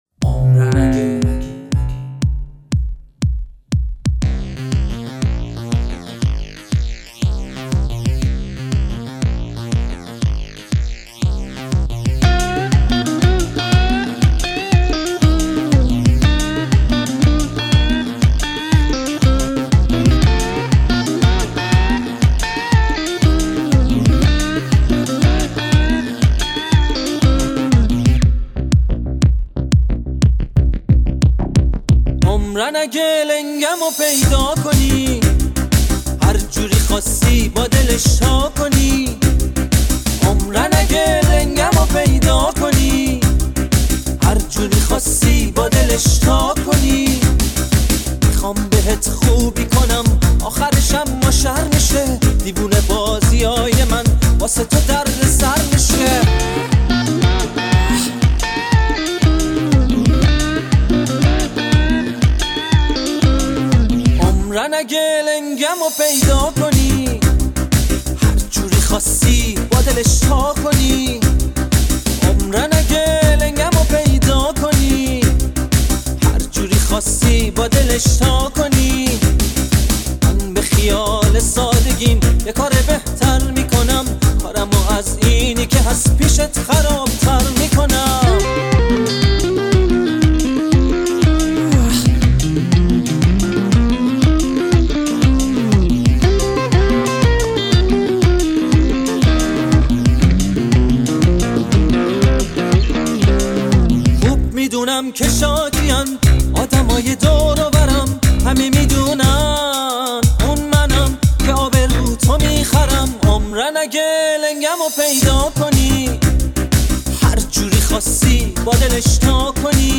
به سبک پاپ است.